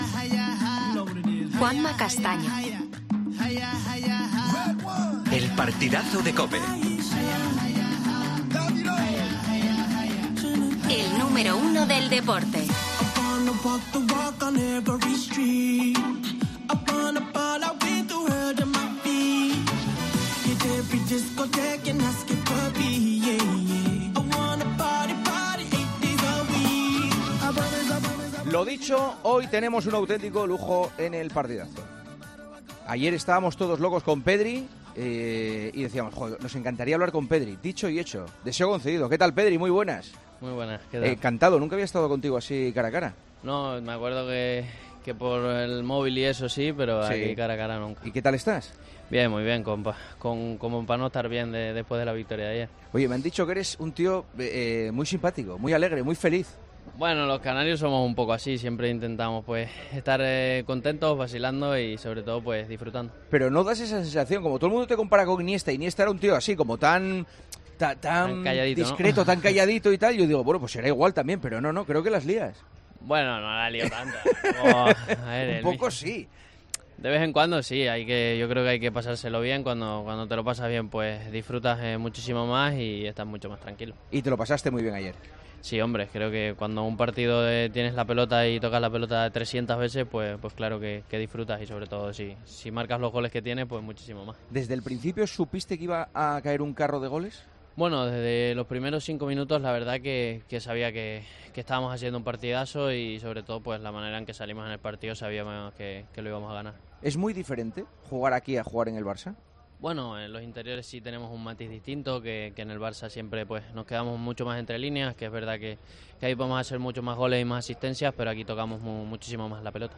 AUDIO: Juanma Castaño entrevistó al centrocampista de España el día después de la goleada de la selección a Costa Rica en su debut en el Mundial.